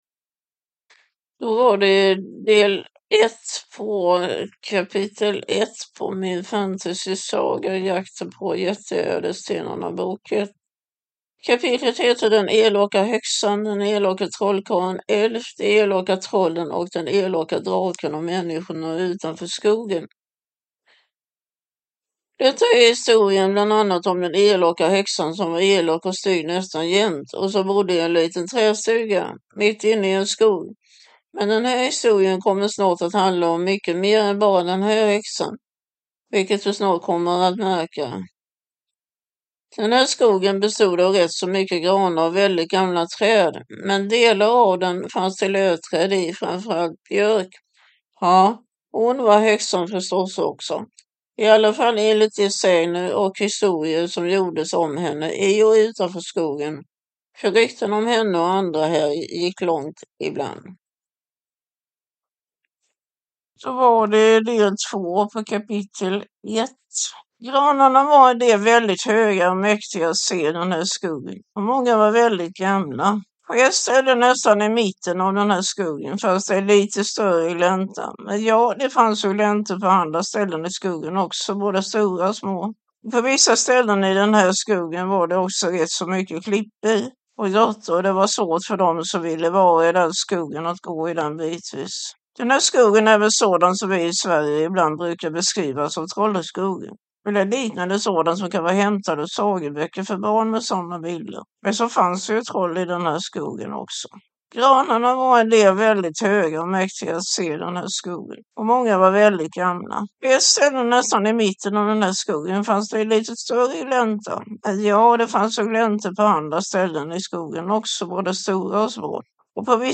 Jakten på jätteädelstenarna, bok 1 (ljudbok) av Anna K Olson